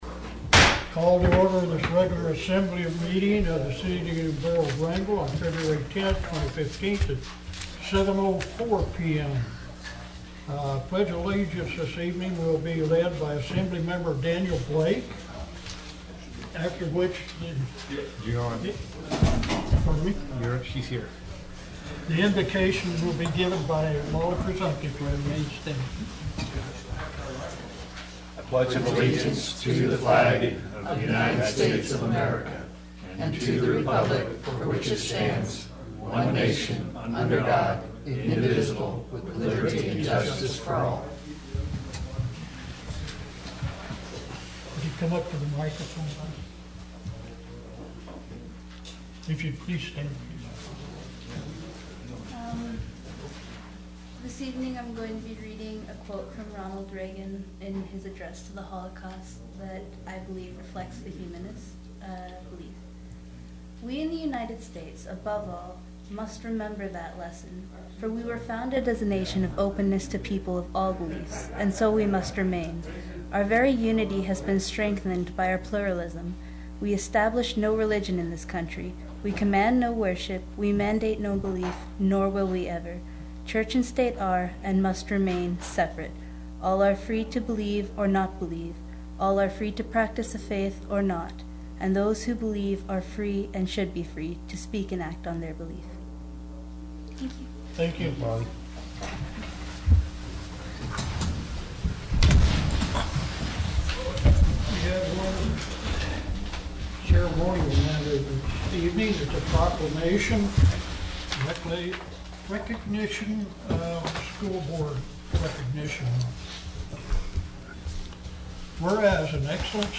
Wrangell's Borough Assembly met for a regular meeting Tuesday, Feb. 10 in the Assembly Chambers.
City and Borough of Wrangell Borough Assembly Meeting AGENDA February 10, 2015–7 p.m. Location: Assembly Chambers, City Hall